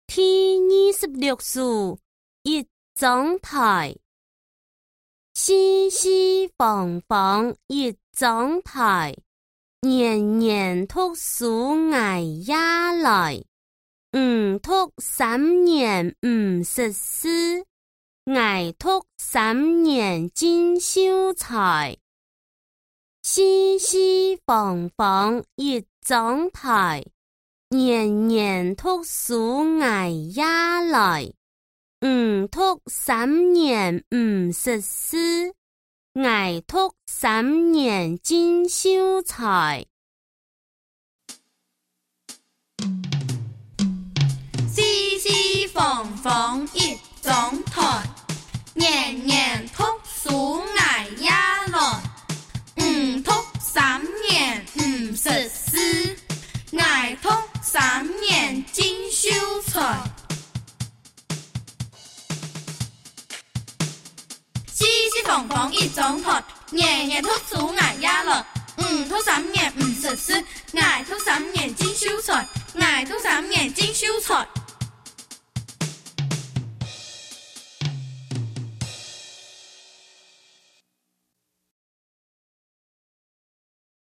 標題：客語歌謠連結